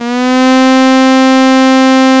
STRS C3 S.wav